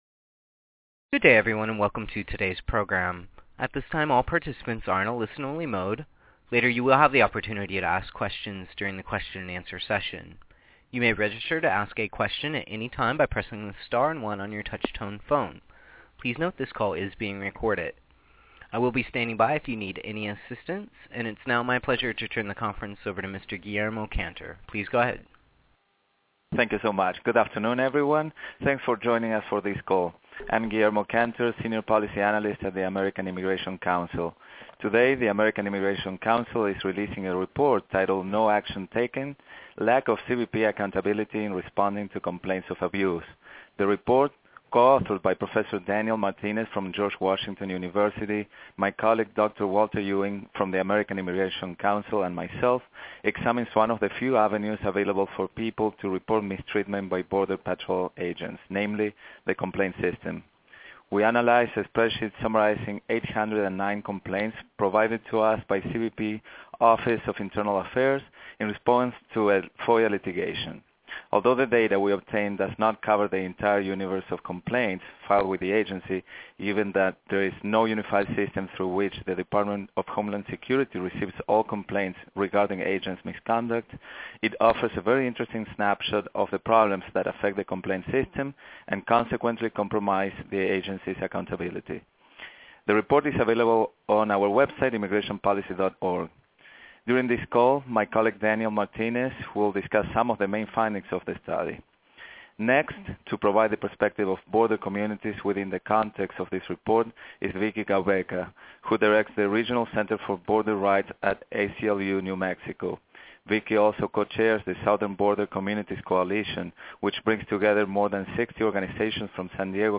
No Action Taken Tele-briefing.mp3